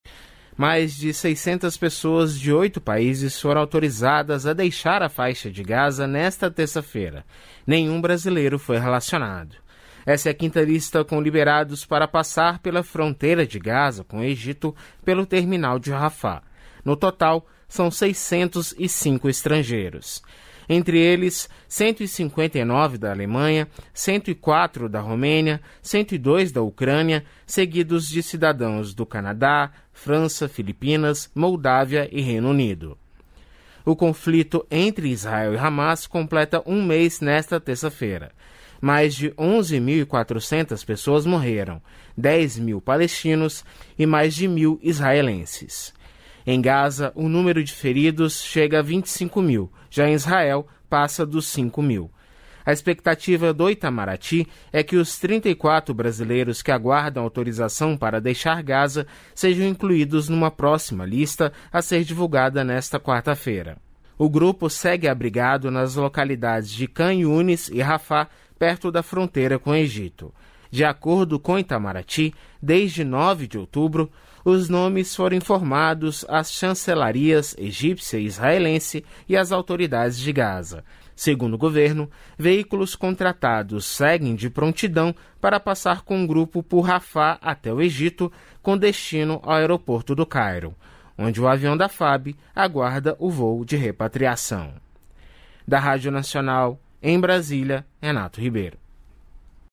Repórter da Rádio Nacional guerra Israel Hamas Gaza terça-feira